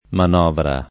[ man 0 vra ]